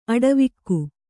♪ aḍavikku